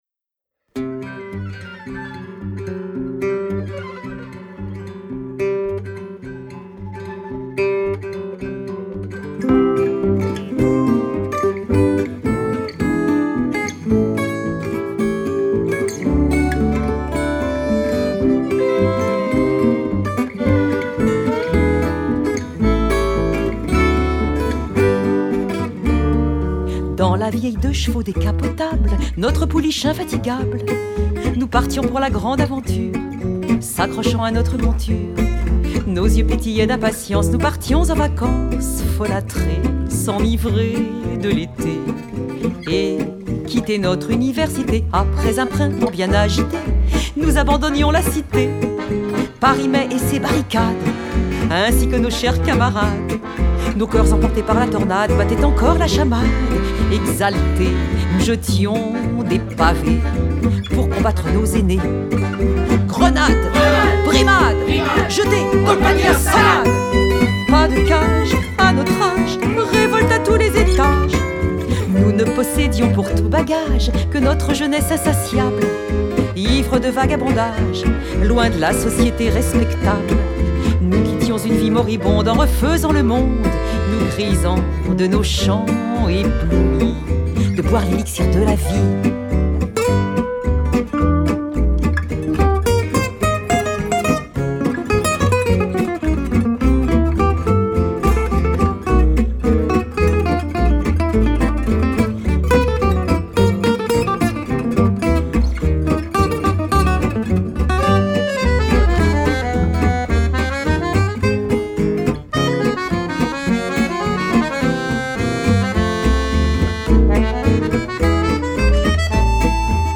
accordéon, voix
guitare
contrebasse